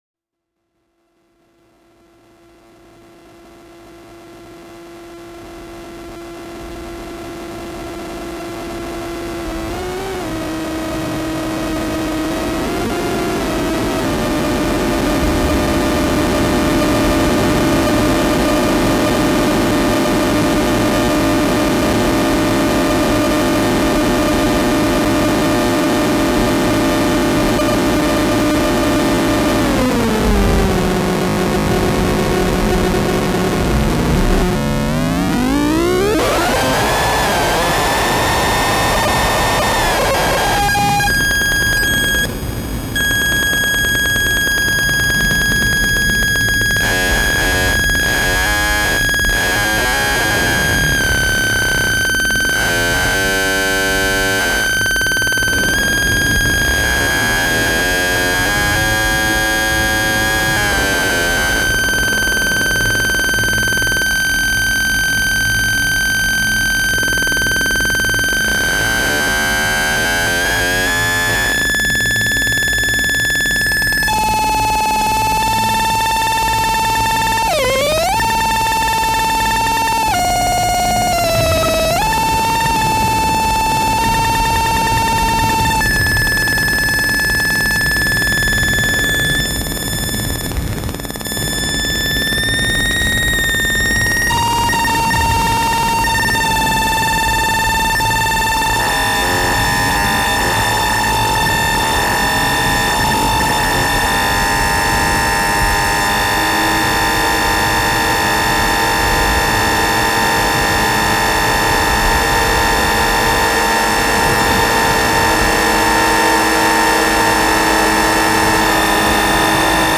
4 channel 63:00 audio, Hypersonic directional speakers